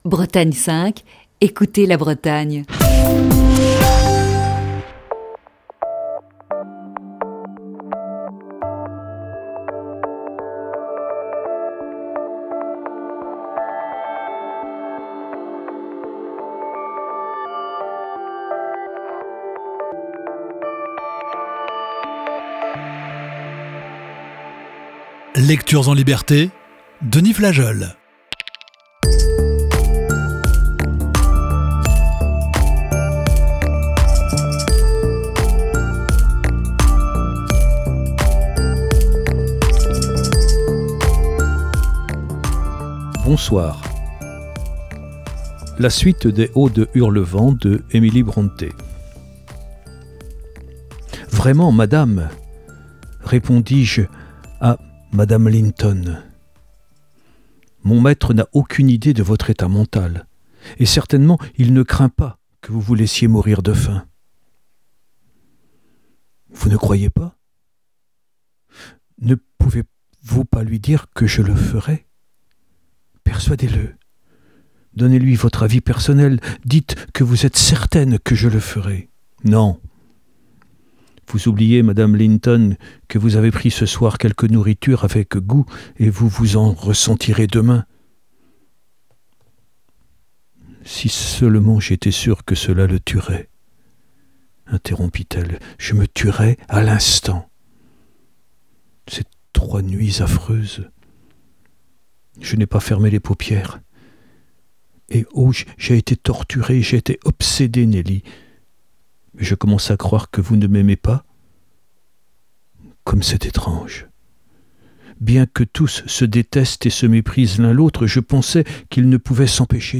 lit le célèbre roman d'Emily Brontë "Les Hauts de Hurlevent". Voici ce mercredi la treizième partie de ce récit.